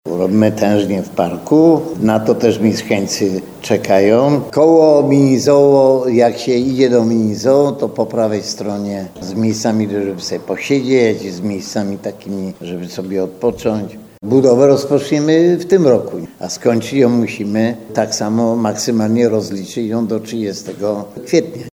Mówi Antoni Szlagor, burmistrz miasta.